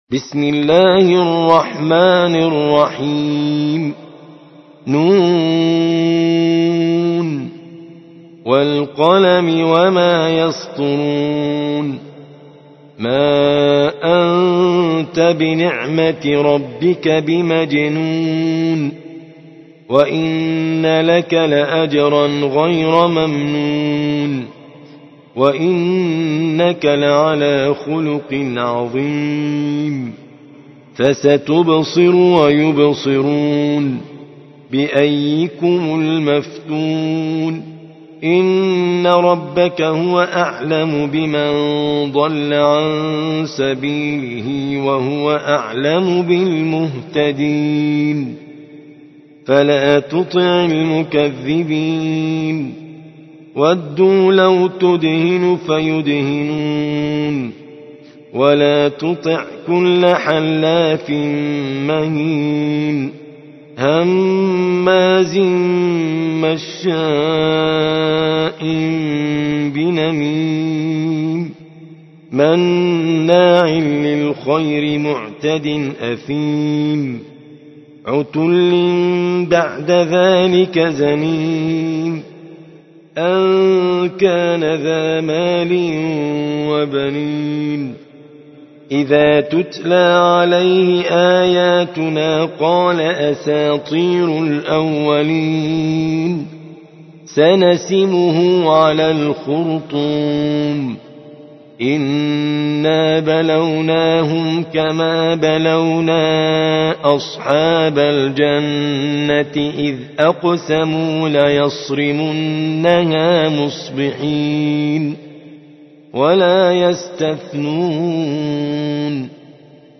68. سورة القلم / القارئ